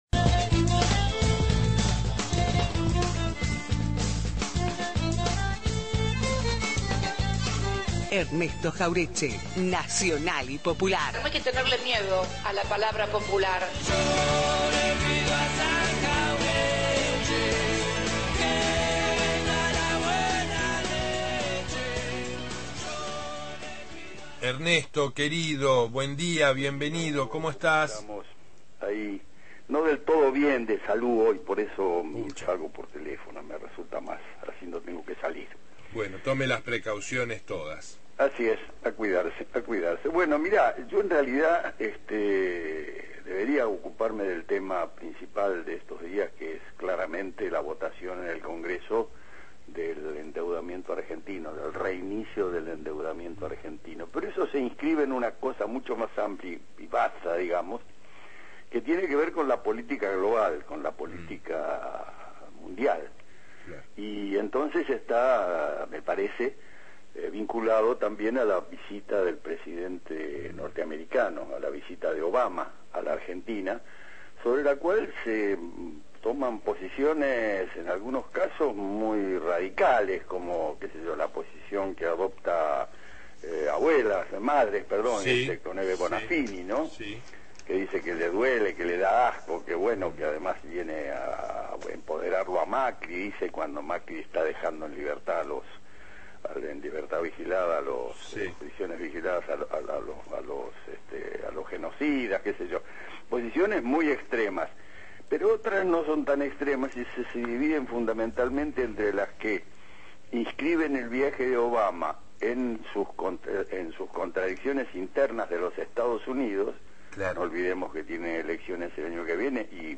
columna de política de la patria grande